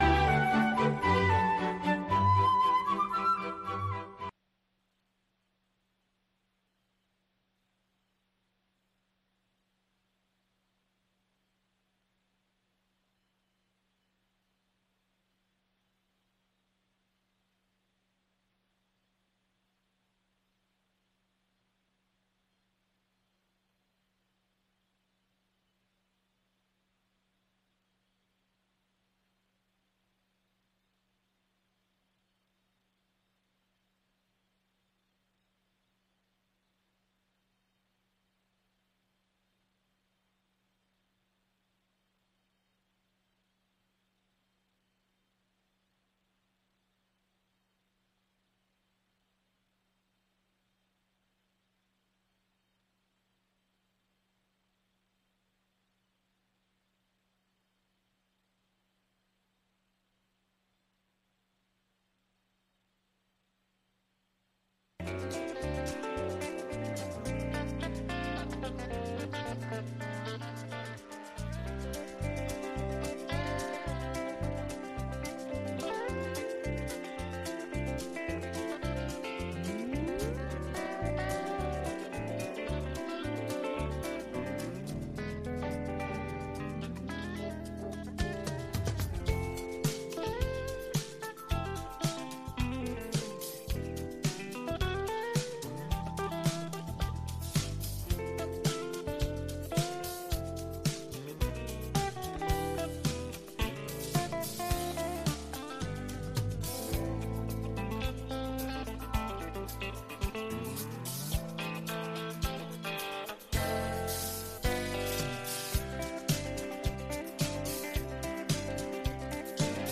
VOA 한국어 아침 뉴스 프로그램 '워싱턴 뉴스 광장' 2021년 10월 23일 방송입니다. 북한이 가능한 모든 영역에서 핵 개발에 전력을 다하고 있다고 국제원자력기구(IAEA) 사무총장이 지적했습니다. 미국은 제재 사용에 관해 동맹· 파트너 국가들과의 협의와 협력을 중시할 것이라고 미 재무부 부장관이 밝혔습니다. 북한이 여전히 대량살상무기 기술 이전 역할을 하고 있다고 낸시 펠로시 미 하원의장이 지적했습니다.